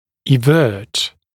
[ɪ’vɜːt][и’вё:т]выворачивать наизнанку, наружу